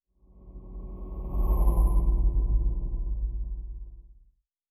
pgs/Assets/Audio/Sci-Fi Sounds/Movement/Fly By 06_4.wav at master
Fly By 06_4.wav